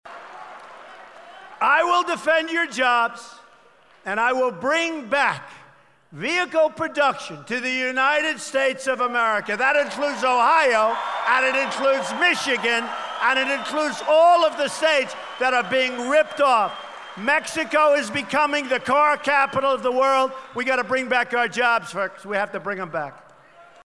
Donald Trump refreshed his attack on trade deals during his speech in Canton last night by noting that Ford is planning to eventually shift all small-car production to Mexico. He said it would be easy to change Ford’s mind with a 4 to 5 minute phone call explaining that every vehicle made overseas would be subject to a 35 percent import tariff.